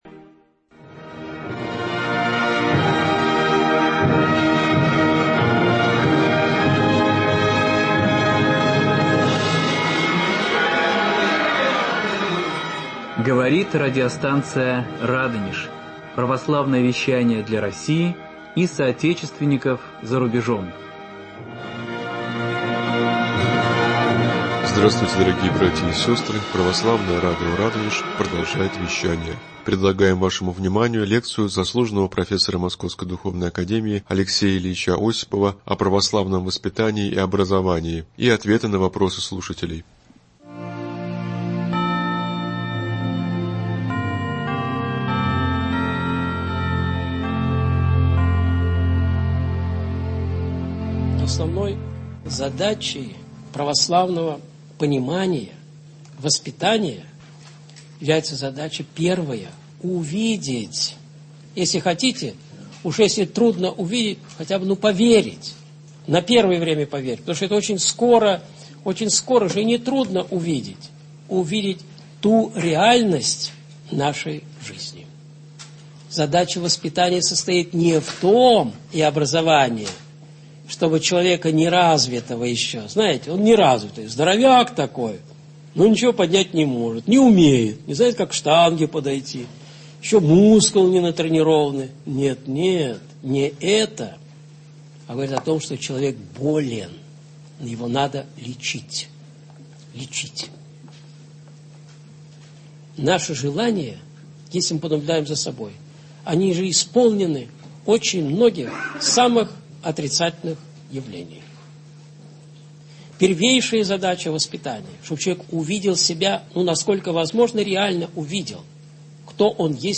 послушать 20.07.24 В эфире радио «Радонеж»